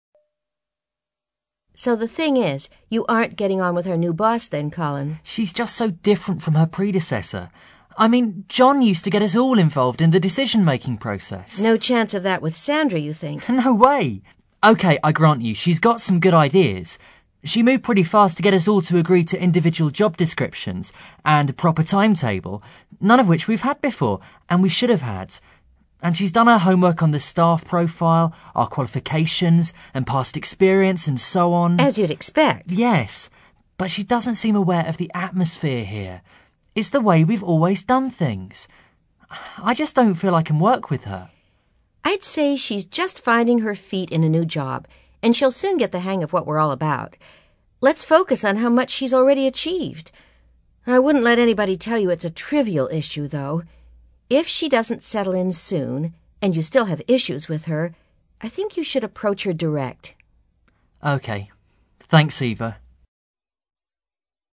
You hear two colleagues, Eva and Colin, talking about a problem at work.